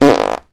Fart: Loud And Close.